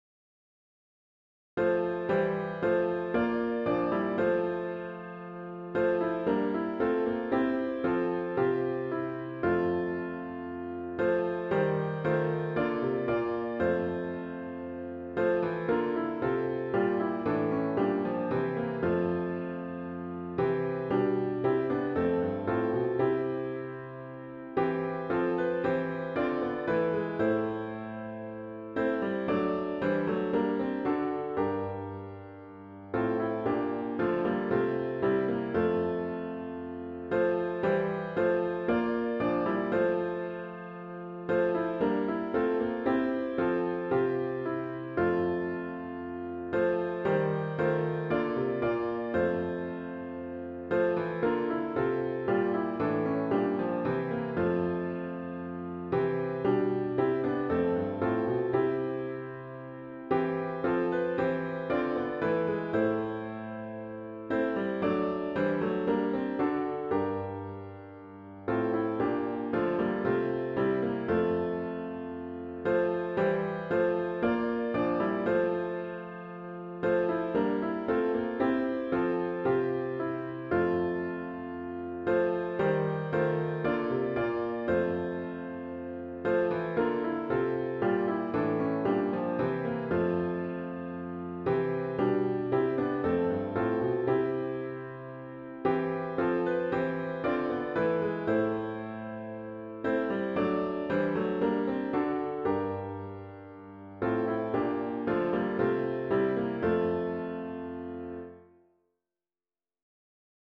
OPENING HYMN   “Now Thank We All Our God”   GtG 643